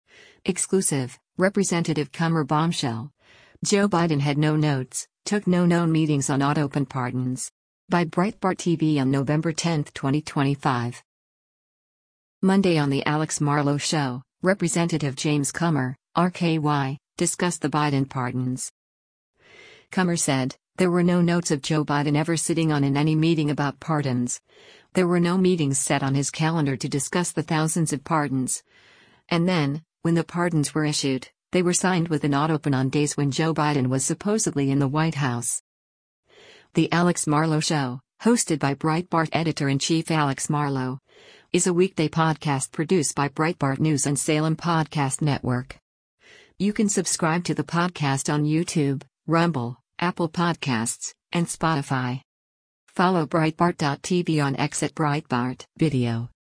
is a weekday podcast produced by Breitbart News and Salem Podcast Network.